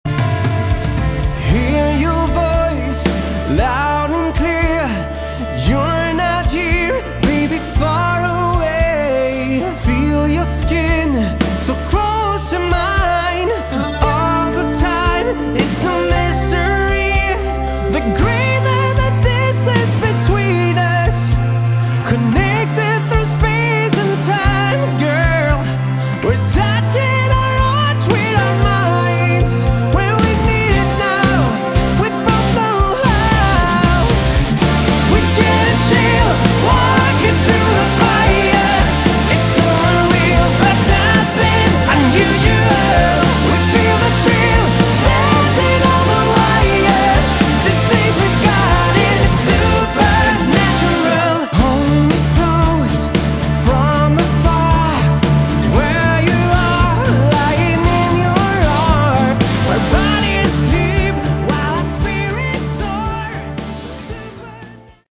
Category: AOR / Melodic Rock
lead vocals
guitars, backing vocals, keyboards
bass
drums